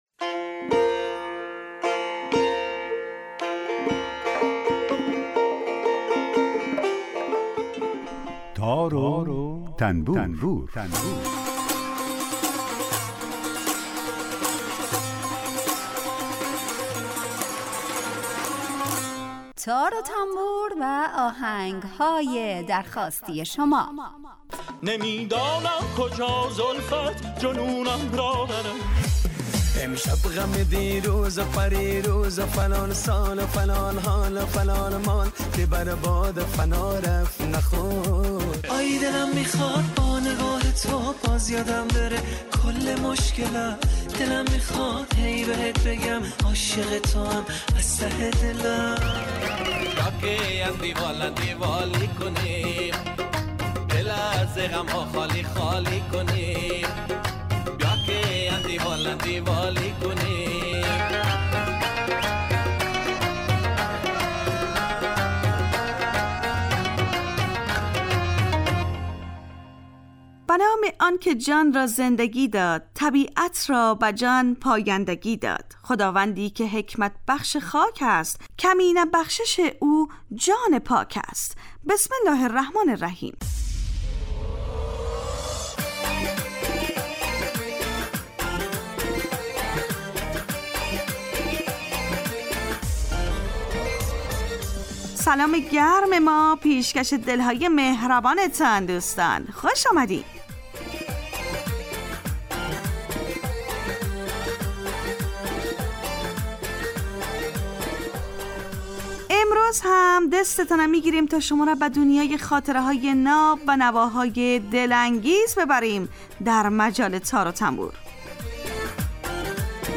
برنامه تار و تنبور هر روز از رادیو دری به مدت 40 دقیقه برنامه ای با آهنگ های درخواستی شنونده ها کار از گروه اجتماعی رادیو دری.
در این برنامه هر یه آیتم به نام در کوچه باغ موسیقی گنجانده شده که به معرفی مختصر ساز ها و آلات موسیقی می‌پردازیم و یک قطعه بی کلام درباره همون ساز هم نشر میکنیم